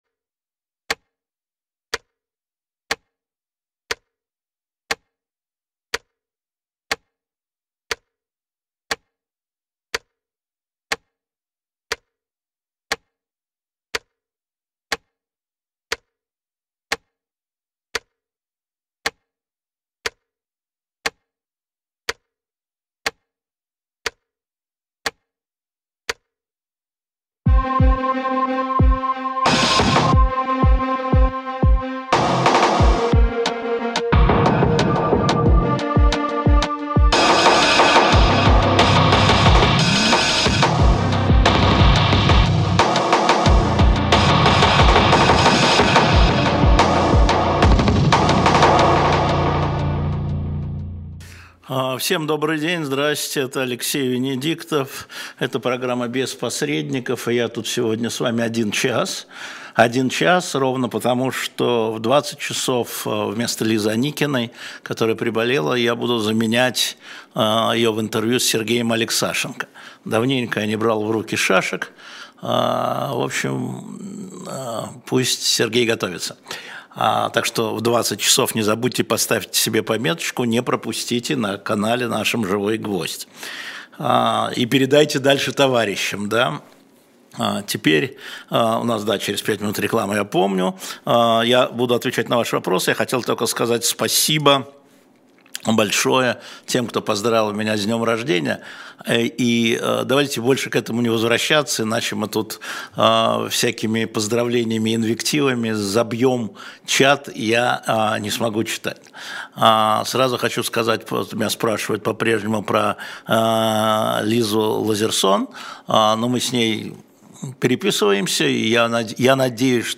Эфир Алексея Венедиктова